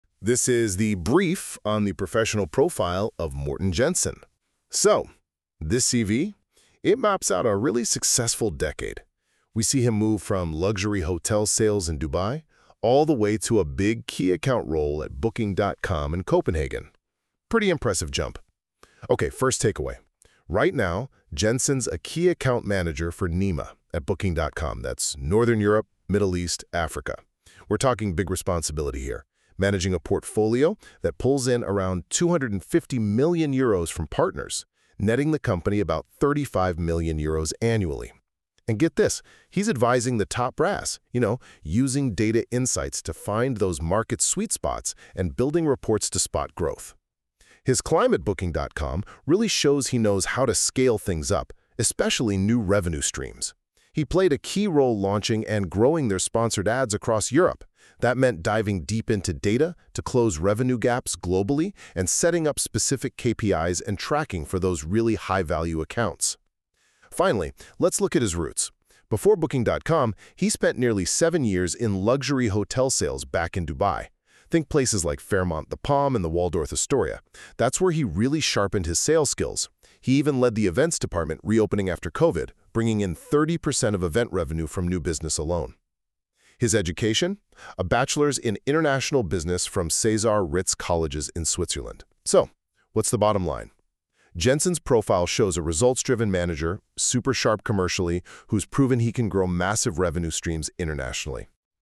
The CV recording is generated on NotebookLM - a fun take on presenting a professional background but as with many AI tools, it’s not quite flawless